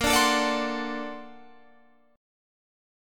A#+M9 chord